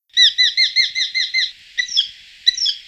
Faucon hobereau
falco subbuteo
f_hobereau.mp3